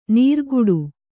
♪ nīrguḍu